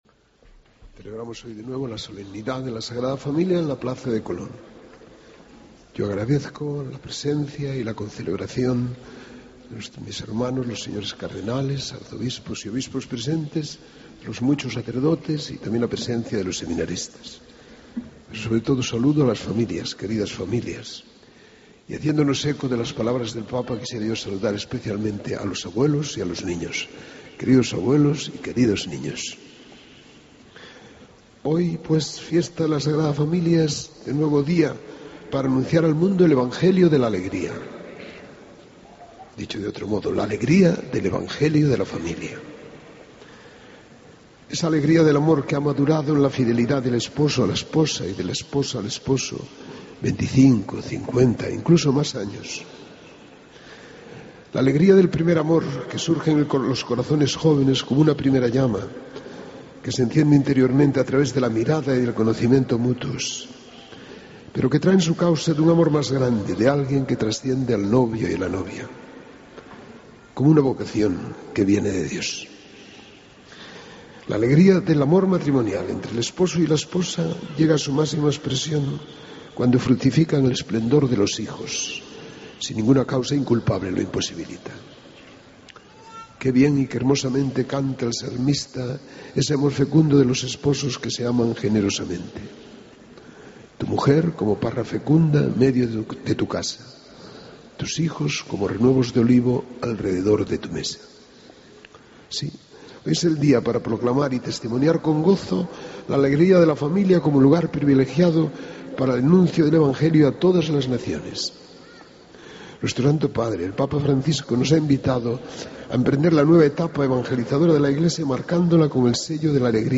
Escucha la Homilía de Monseñor Rouco Varela durante la Santa Misa de la Fiesta de la Sagrada Familia
AUDIO: El cardenal Arzobispo de Madrid preside la Eucaristía de la Fiesta de la Sagrada Familia en la Plaza de Colón en Madrid.